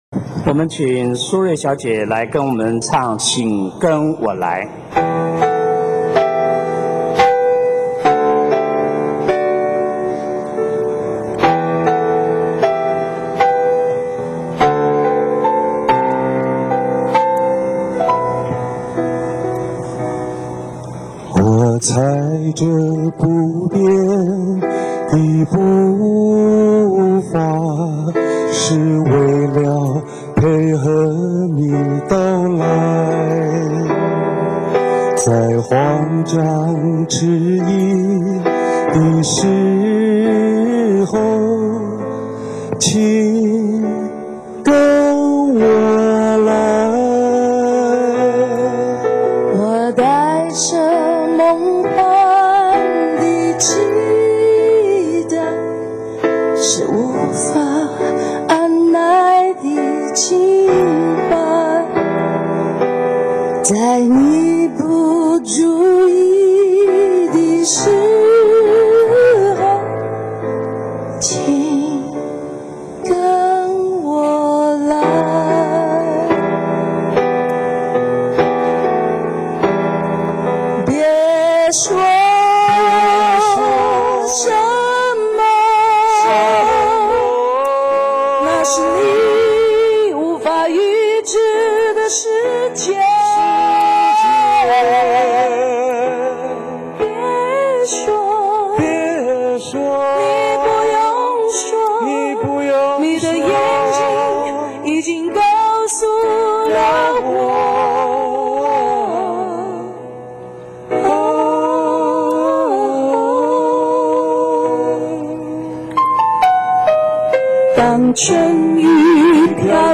二〇〇四年十一月十二日〈星期五〉，天主教会于台北圣家堂为梁弘志举行追思弥撒，弥撒进行中有多位音乐人唱出梁弘志所写的歌曲。